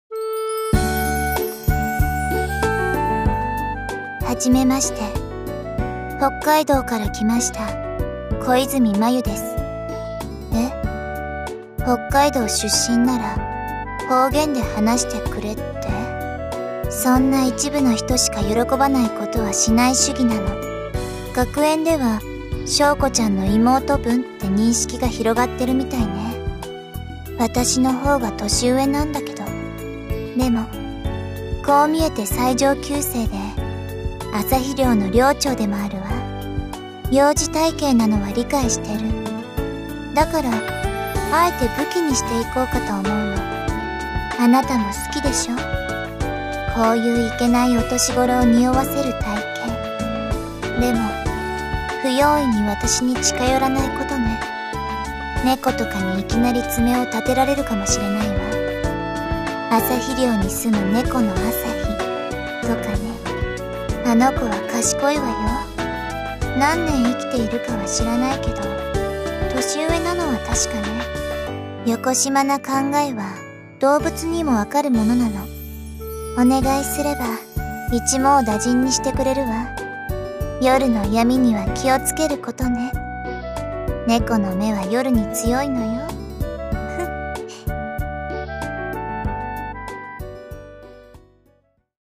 ドラマボイス 自己紹介編 ドラマボイス　啓人編 character introduction 繭 紹介フラッシュ PAGE UP